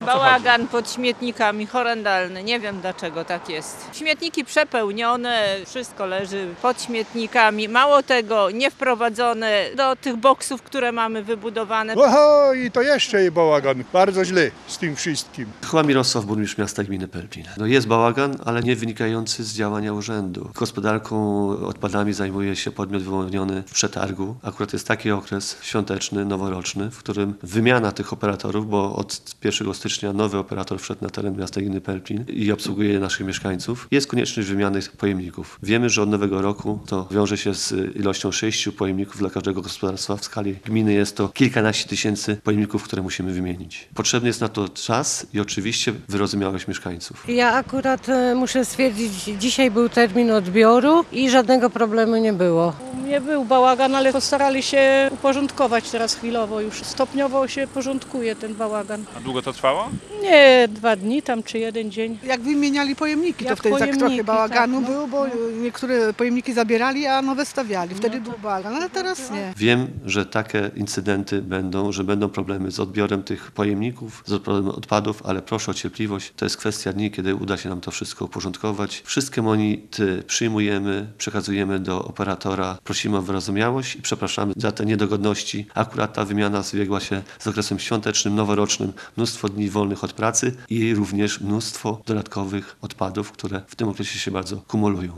– Jest bałagan pod śmietnikami. Pojemniki są przepełnione, niewprowadzone do boksów – mówi naszemu reporterowi mieszkanka Pelplina.